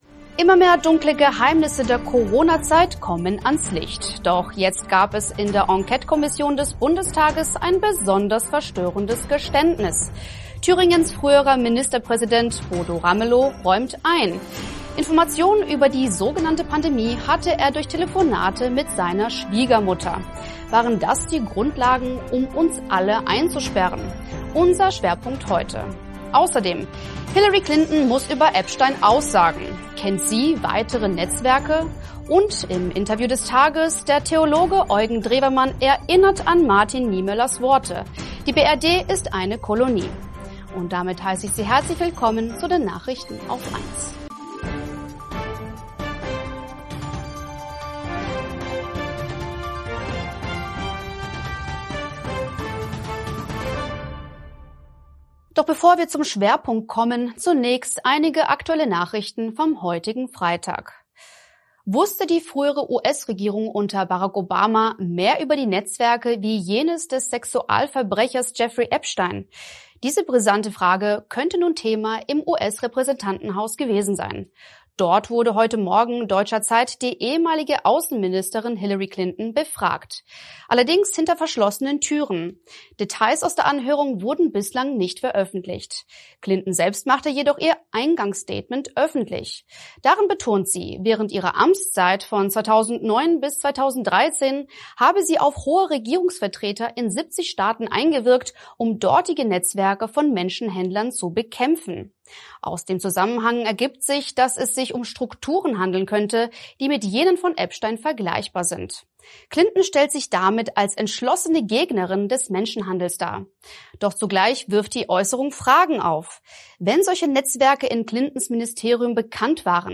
+ Und: Im Interview des Tages: Der Theologe Eugen Drewermann erinnert an Martin Niemöllers Worte: Die BRD ist eine Kolonie.